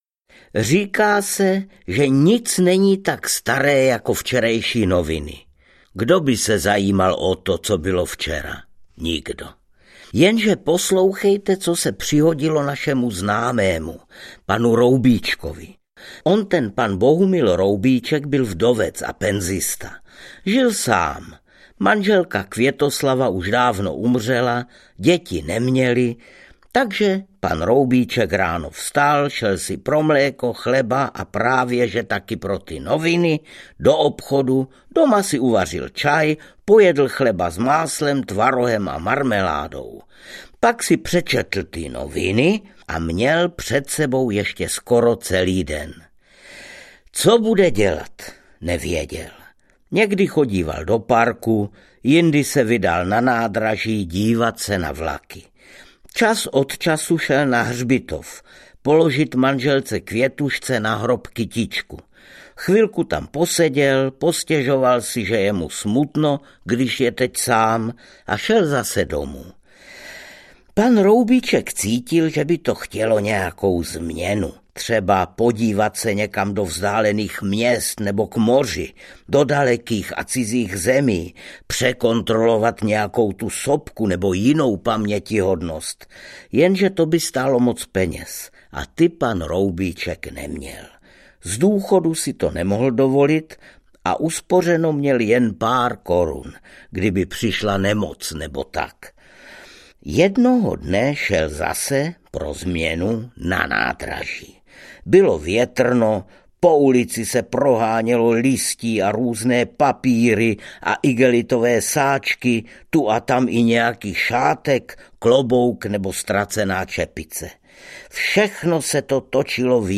Ukázka z knihy
• InterpretArnošt Goldflam